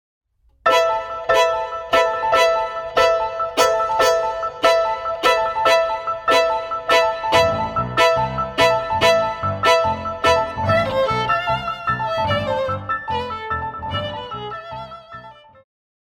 古典,流行
小提琴
钢琴
演奏曲
世界音乐
仅伴奏
没有主奏
没有节拍器